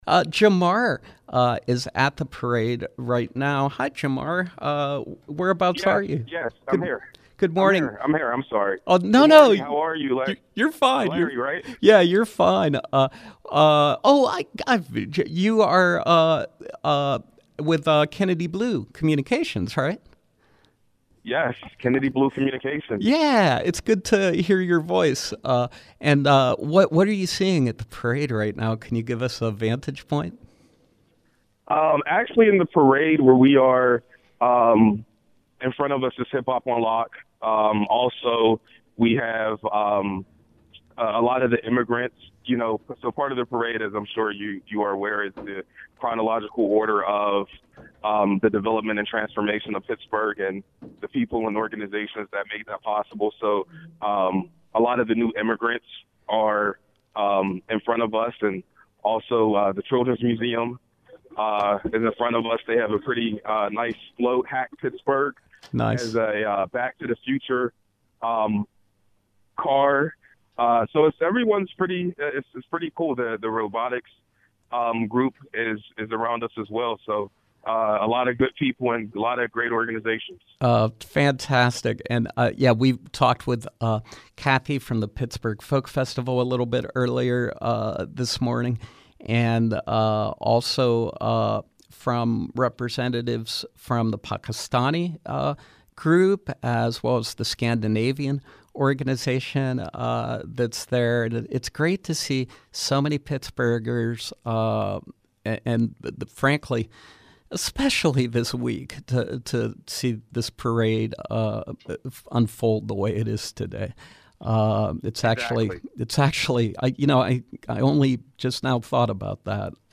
Home » Callers